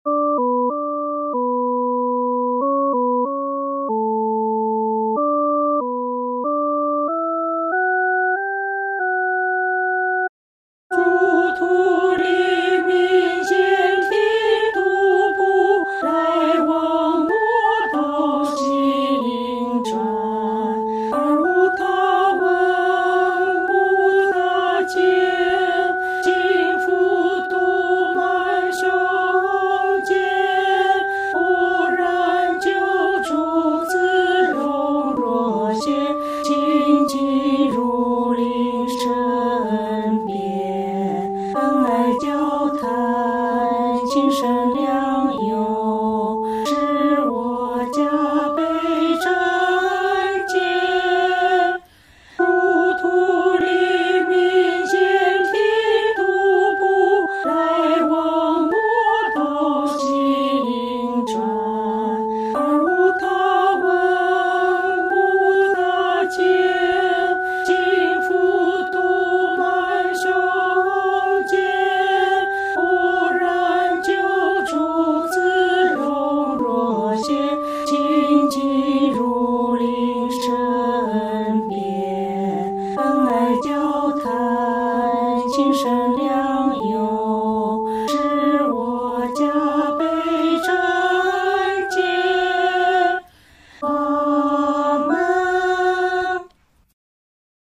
女低